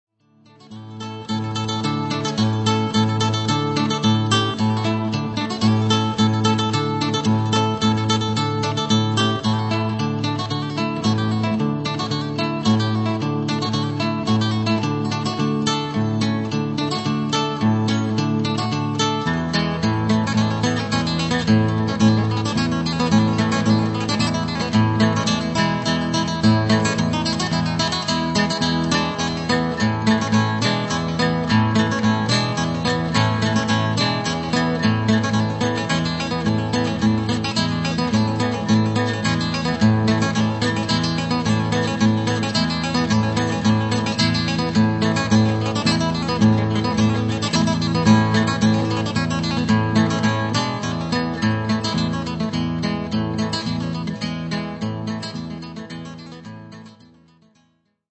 M'arragodu, de piciocheddu pitticcu, su ballu sardu a ghitarra chi s'intendiat a su radieddu prima de su "gazettinu sardu".
ballulestru.wav